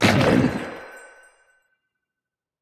Minecraft Version Minecraft Version snapshot Latest Release | Latest Snapshot snapshot / assets / minecraft / sounds / mob / glow_squid / death3.ogg Compare With Compare With Latest Release | Latest Snapshot
death3.ogg